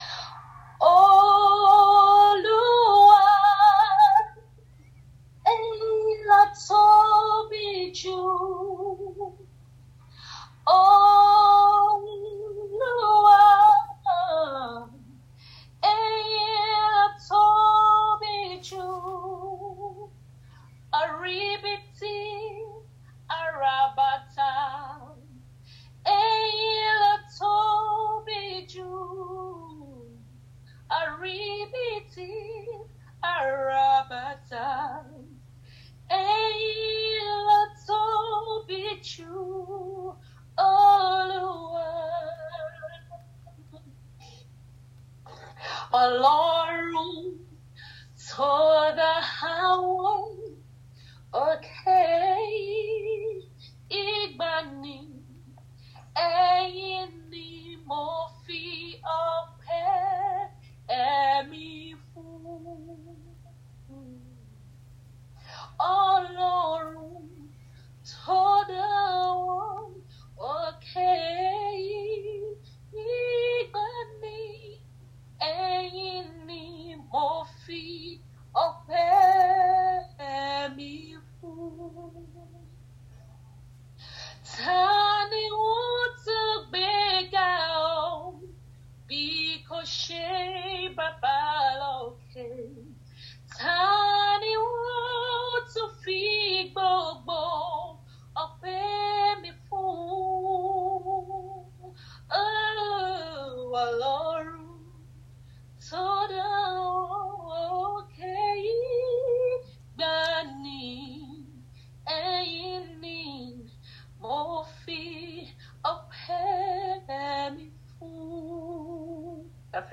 I sing a Yoruba song.
This person also shared every week, every song was great to hear because the person had a beautiful voice and obviously loved to sing.
It was thrilling to hear these traditional songs and her voice and interpretation of the song was so emotional it was impossible not to get swept away in her voice.